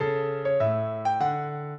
minuet1-8.wav